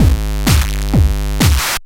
DS 128-BPM A5.wav